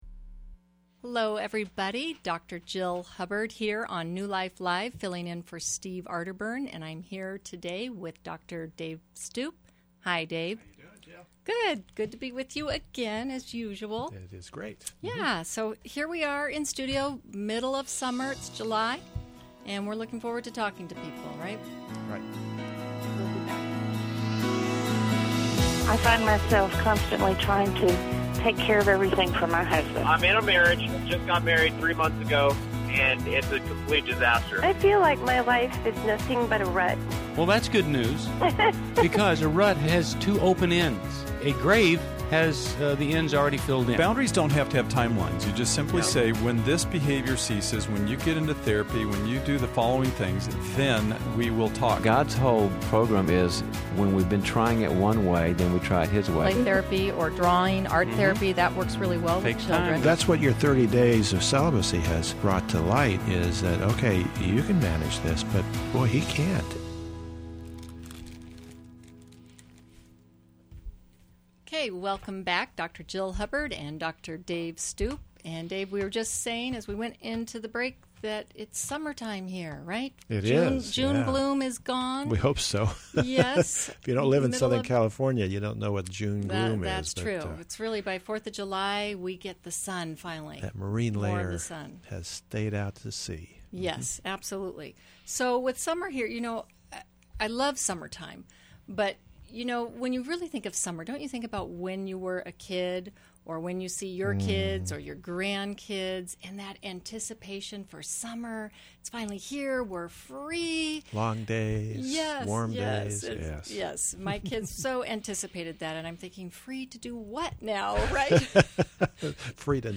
Experts tackle tough questions from callers seeking guidance.